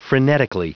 Prononciation du mot frenetically en anglais (fichier audio)
Prononciation du mot : frenetically